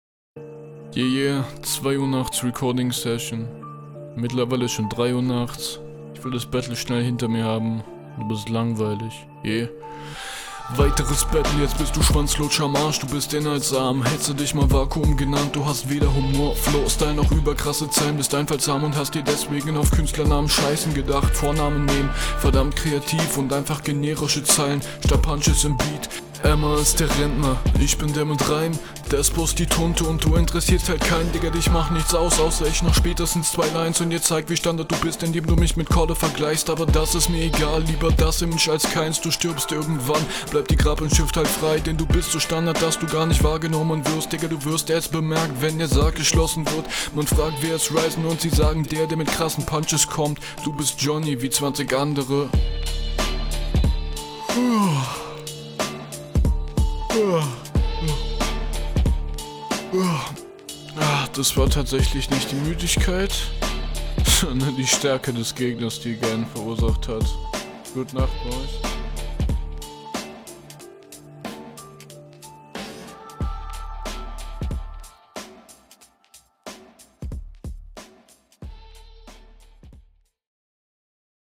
Stimmlich leider passend zur Uhrzeit....viel zu schwach und mau!